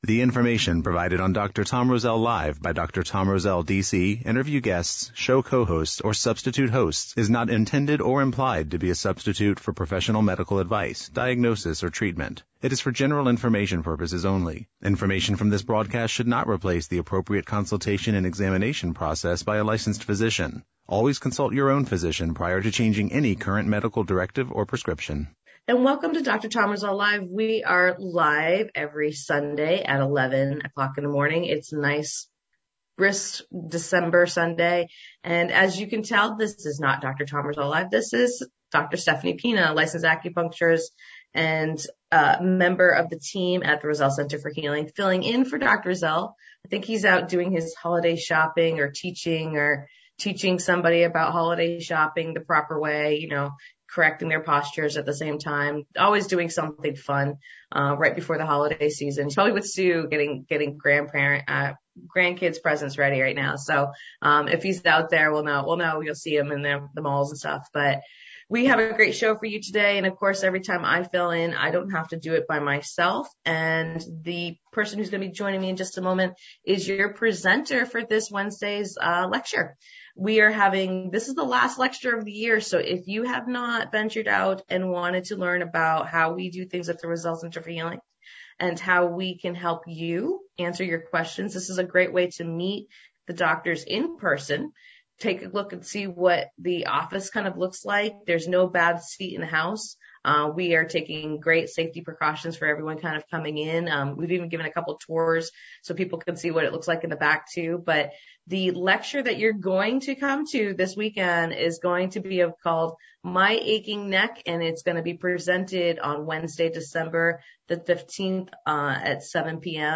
Information from this broadcast should not replace the appropriate consultation and examination process by a licensed Physician.